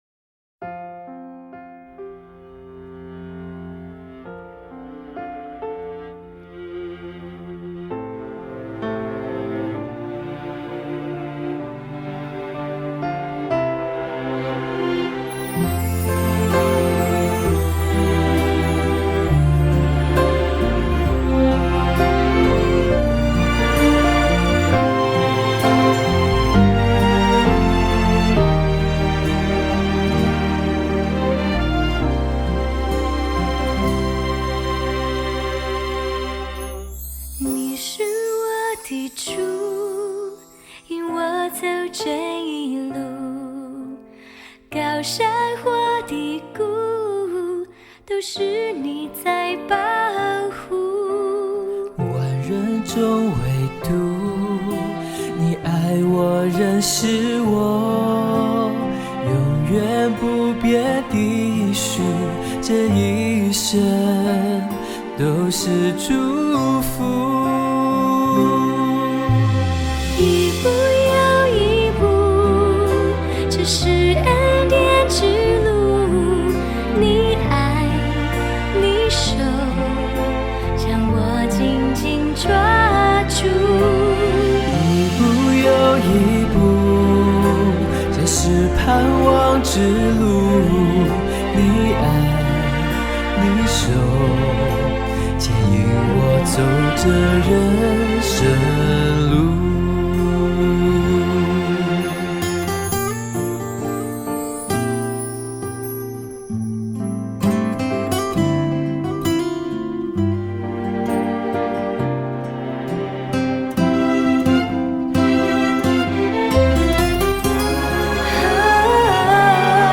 以原声（Acoustic）的方式呈现